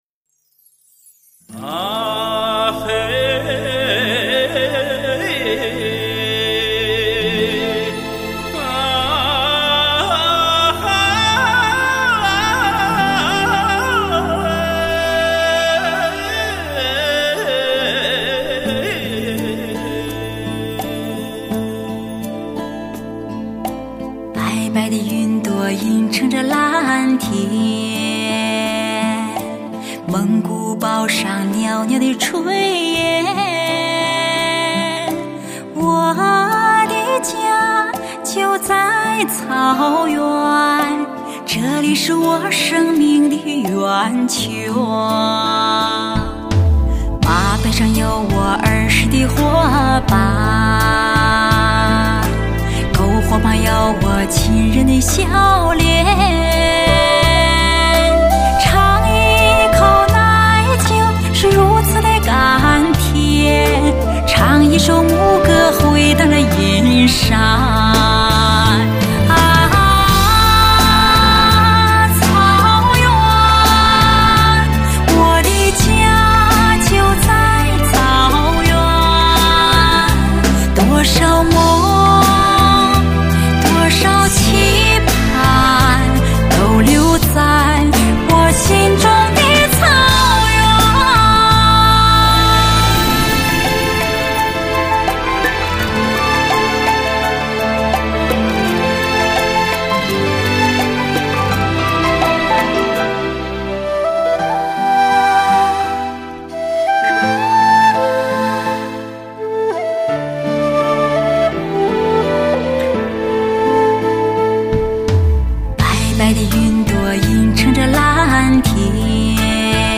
全方位多位环绕
发烧老情歌 纯音乐
极致发烧HI-FI人声测试碟